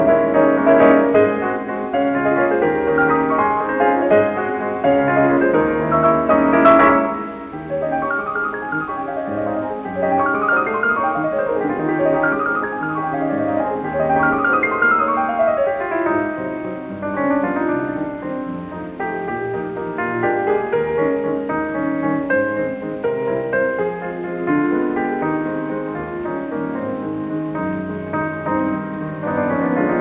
Liszt Franz - 1811-1886 - piano
Waltz, op. 42, la bémol majeur
"enPreferredTerm" => "Musique classique"